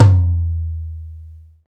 TOM XTOMLO0F.wav